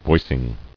[voic·ing]